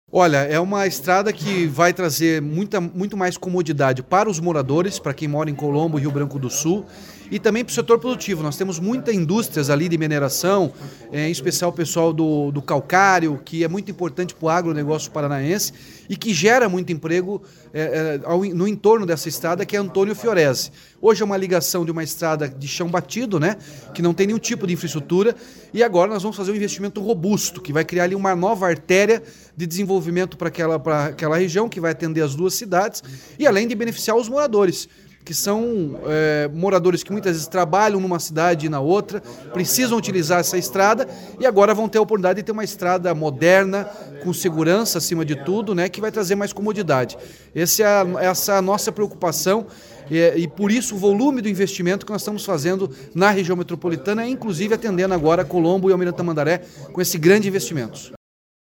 Sonora do governador Ratinho Junior sobre a pavimentação de estrada entre Rio Branco do Sul e Colombo